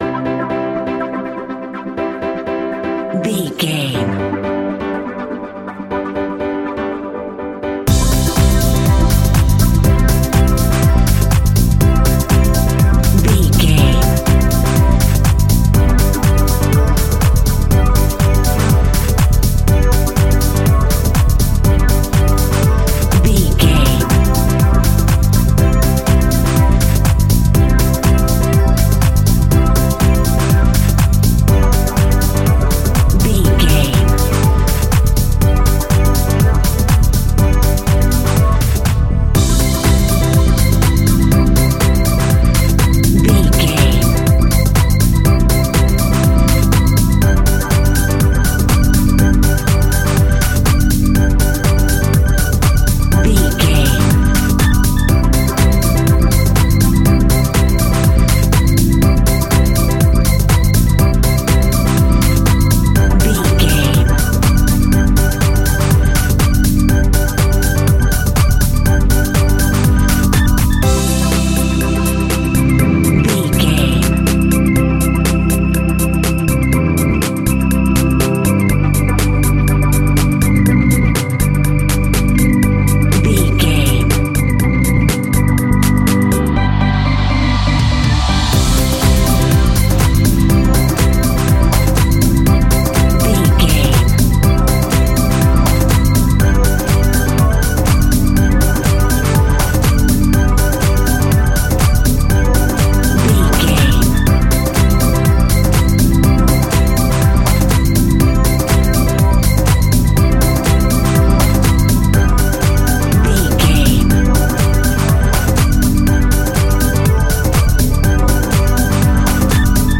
Ionian/Major
groovy
uplifting
driving
energetic
electric piano
bass guitar
electric organ
synthesiser
funky house
disco house
electro funk
upbeat
synth drums
synth bass
clavinet
horns